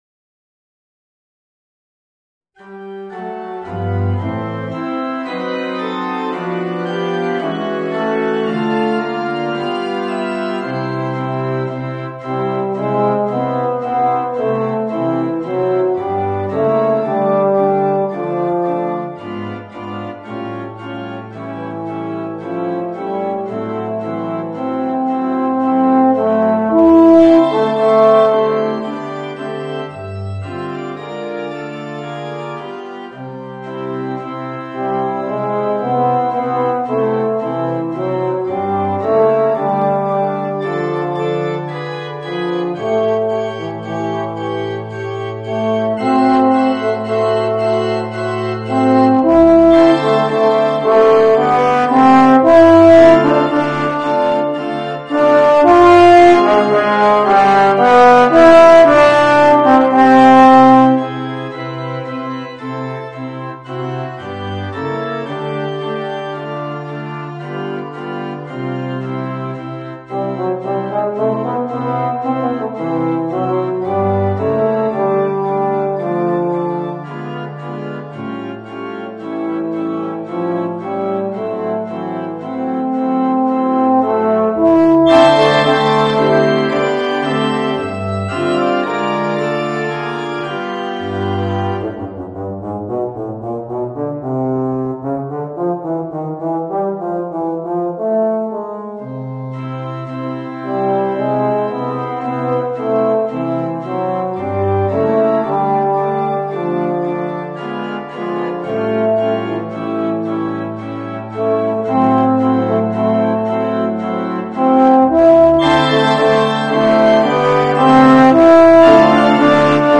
Voicing: Euphonium and Organ